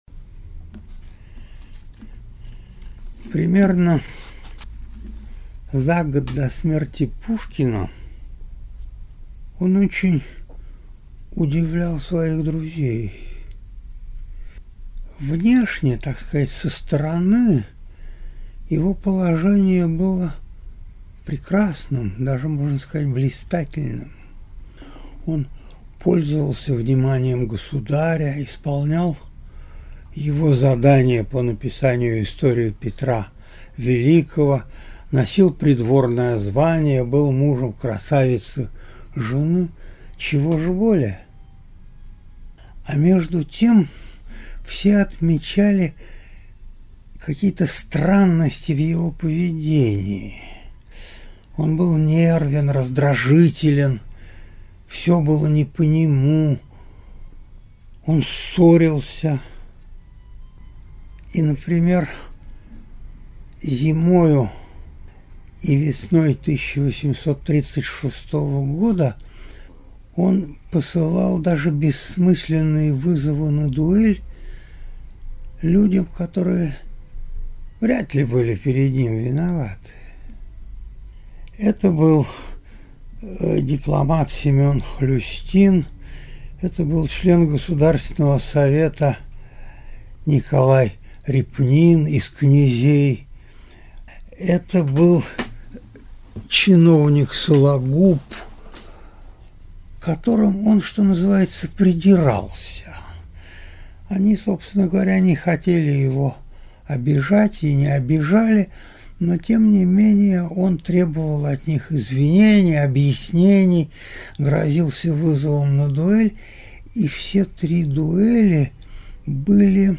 Аудиокнига История Петра и дуэльная история | Библиотека аудиокниг